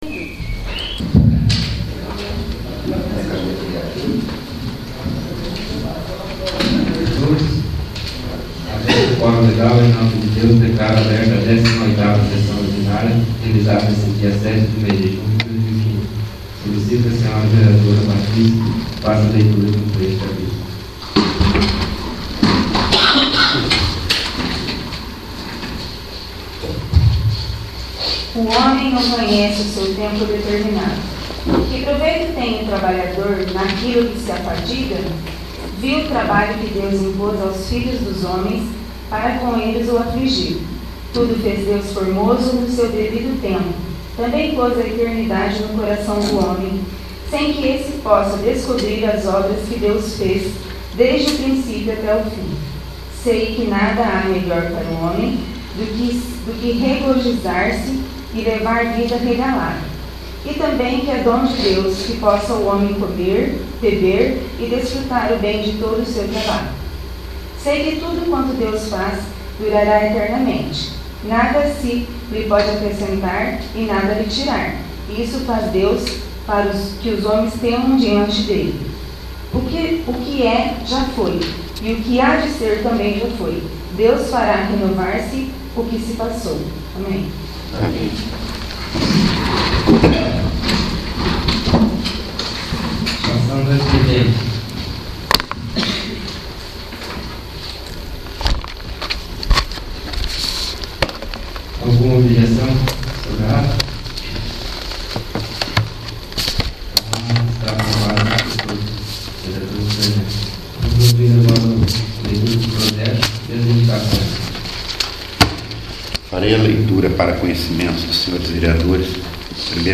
18º. Sessão Ordinária